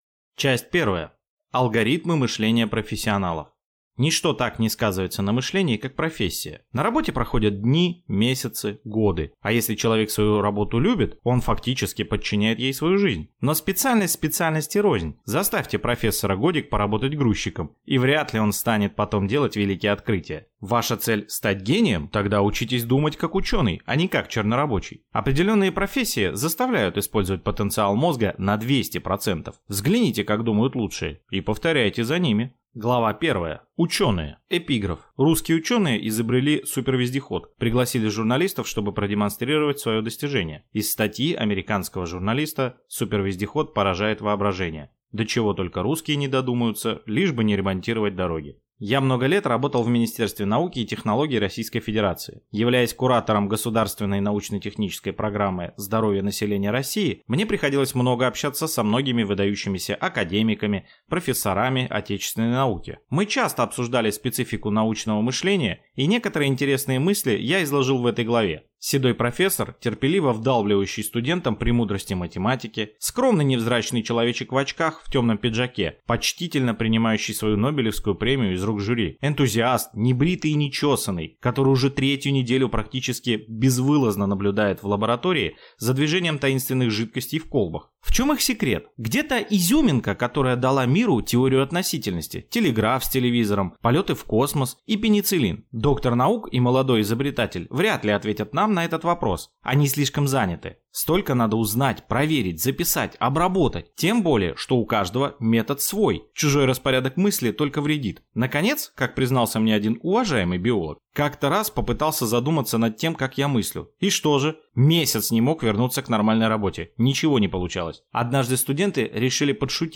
Аудиокнига Умейте мыслить гениально. Как принять решение | Библиотека аудиокниг